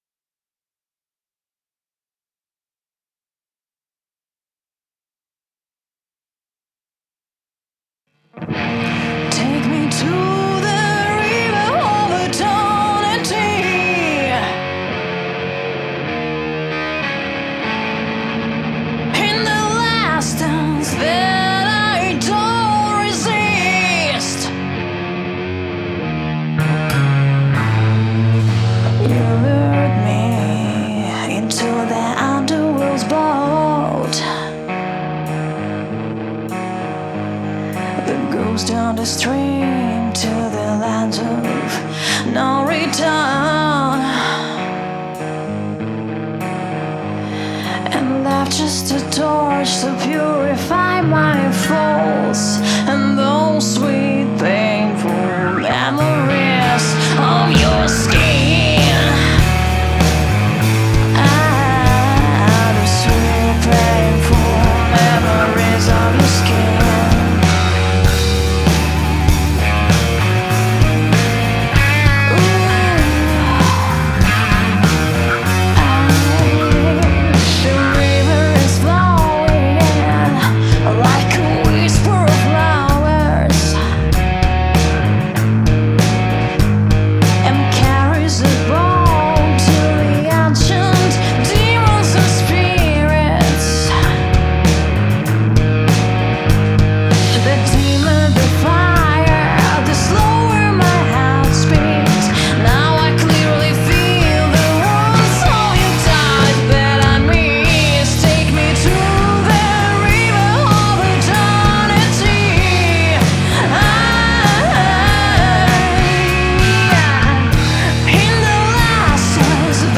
Гаражный грязный матьего блюз-рок
Лайв от начала и до конца, без дублей, квантайза, тюна и тд. Рабочая демка.